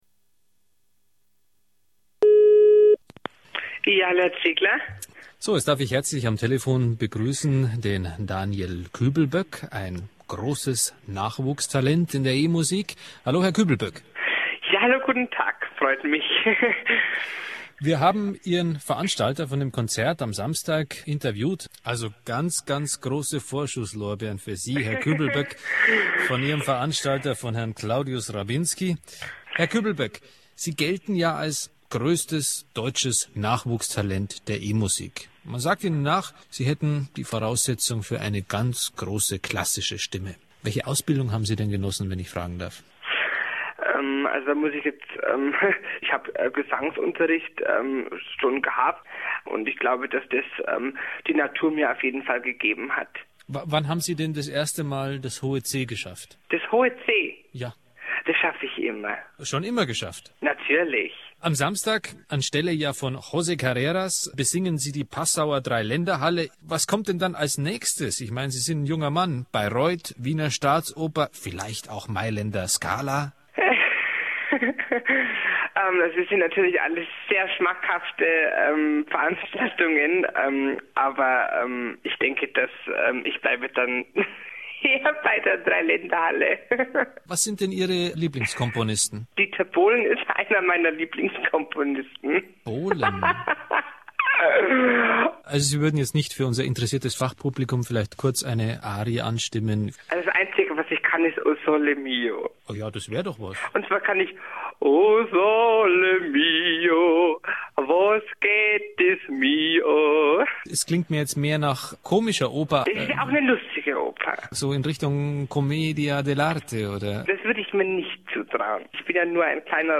Den stellt er in ernsthaftem Tonfall als „Herrn Küblböck – ein großes Nachwuchstalent der E-Musik“ vor.
Daniel meint, dass er nur „O sole mio“ kennt und trällert „O sole mio, wos geht des mi o.“